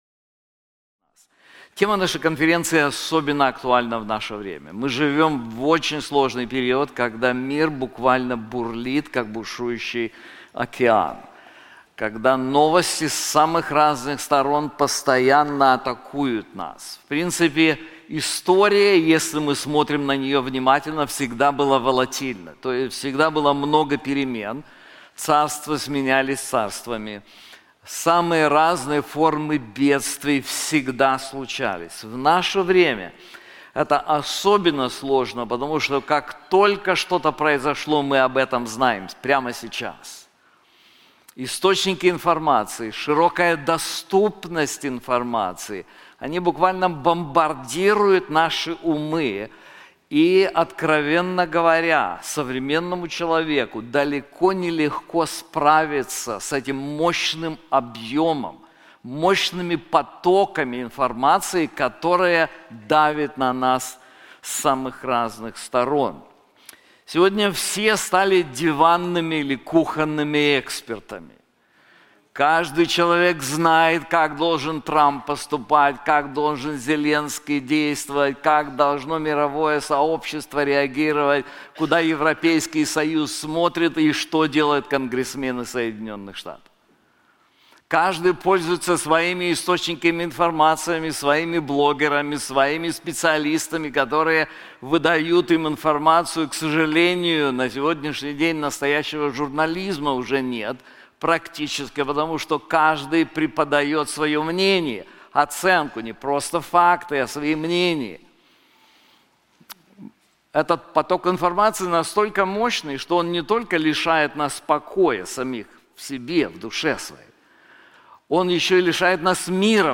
На конференции "Владыка истории" мы рассмотрим книгу пророка Даниила, каждая глава которой ясно показывает, что история находится в руках Бога. Лишь осознав, что нашим миром управляет не хаос, а всевластный Бог, мы сможем уверенно смотреть в будущее, оставаясь верными и усердными в служении.